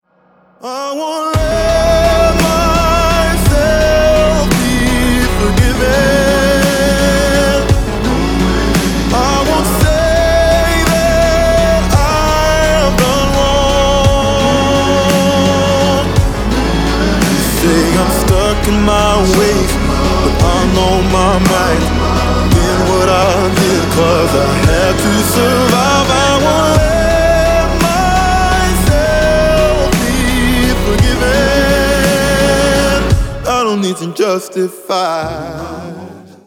• Качество: 320, Stereo
поп
мужской вокал
громкие
alternative